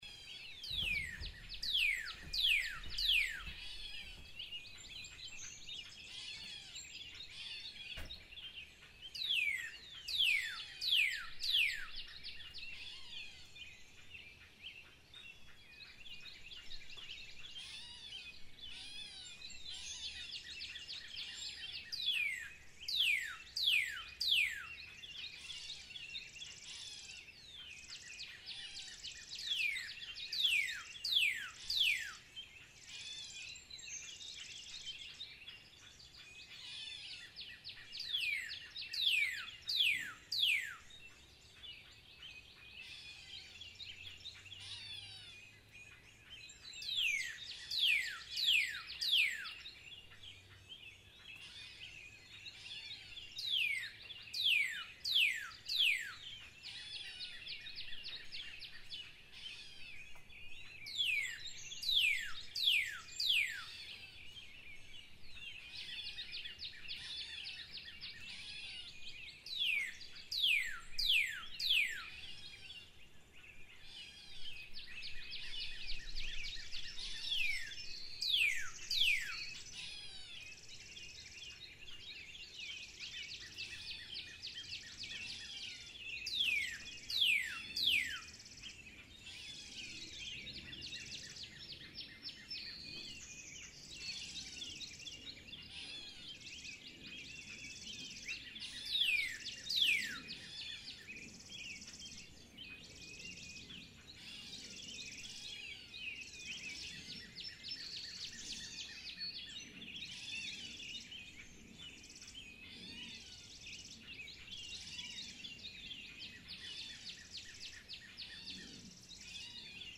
birds